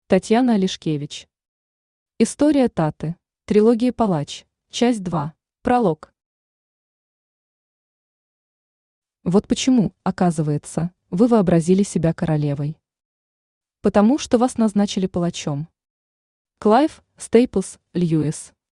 Aудиокнига История Таты Автор Татьяна Олешкевич.